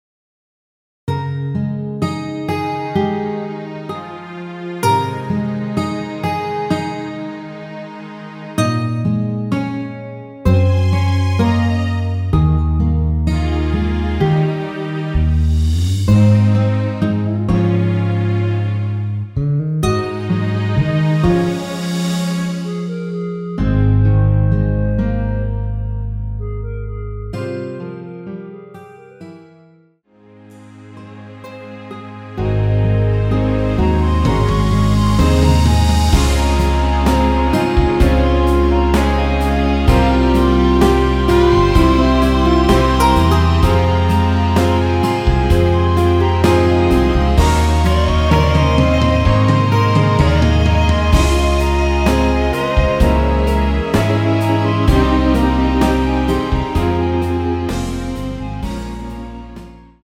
원키 멜로디 포함된 MR입니다.
Ab
앞부분30초, 뒷부분30초씩 편집해서 올려 드리고 있습니다.
중간에 음이 끈어지고 다시 나오는 이유는
(멜로디 MR)은 가이드 멜로디가 포함된 MR 입니다.